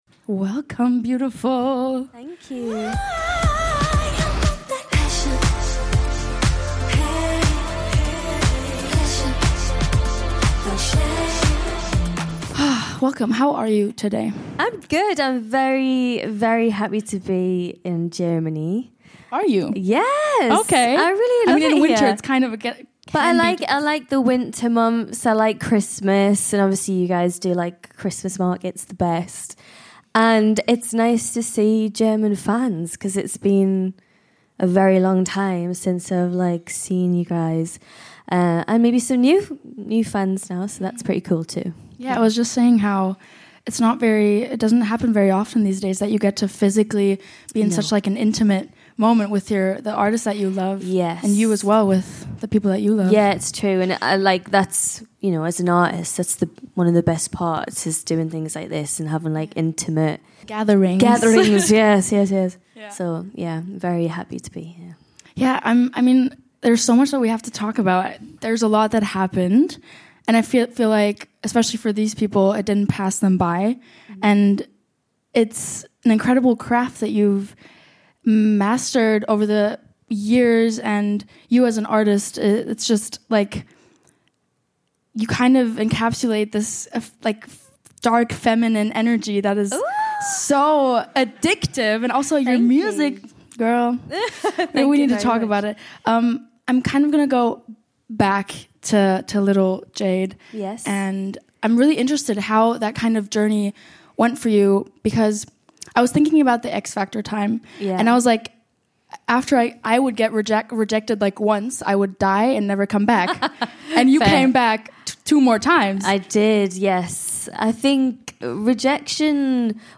I had a long a chat LIVE with JADE and that in front of YOU💅🏻 We talked about her little mix era, which new freedom she got with her solo carrer and a lot of fan questions from the audience.